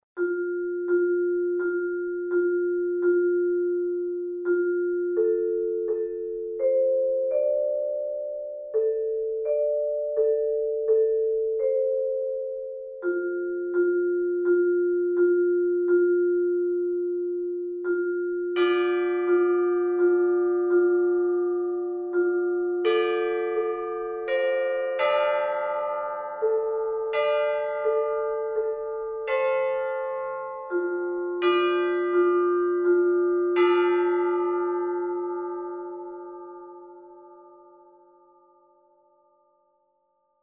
Nacht . . . De morgenster verschijnt